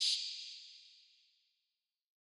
NEW MAGIC WAND Shaker.wav